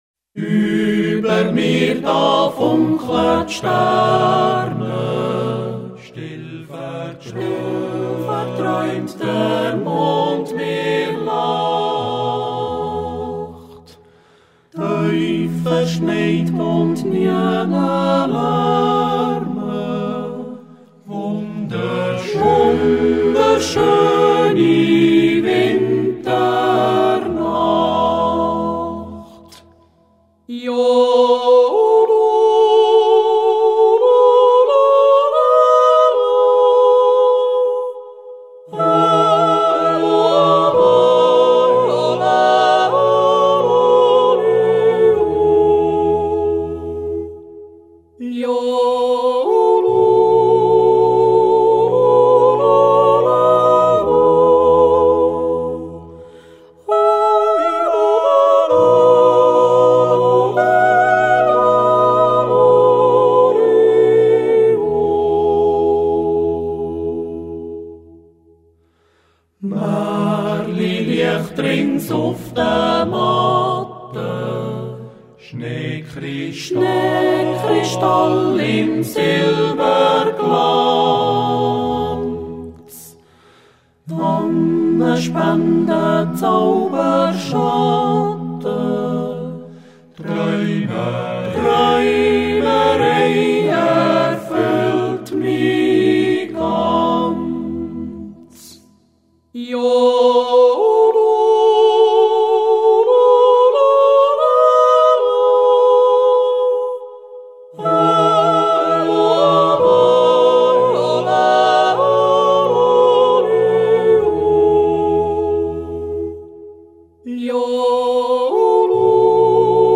small shepherds choir
seven male yodellers
natural yodel, yodel songs and popular dialect songs
Yodel song.